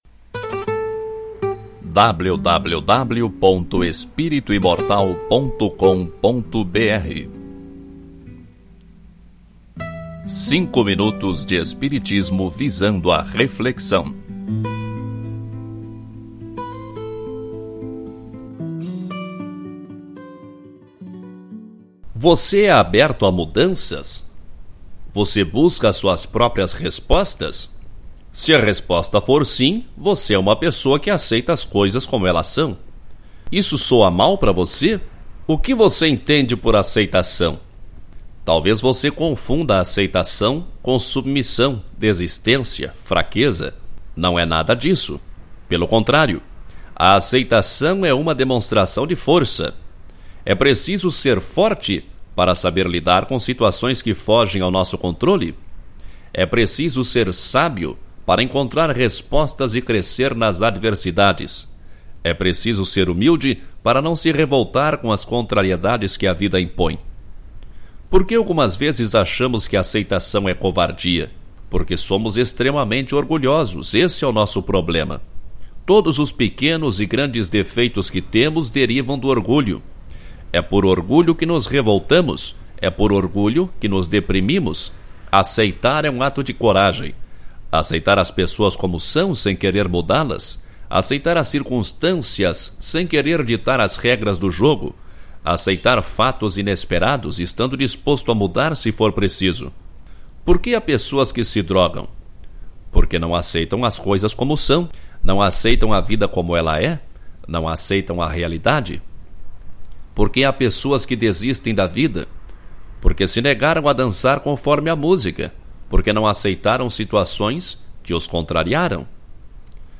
Ouça este artigo na voz do autor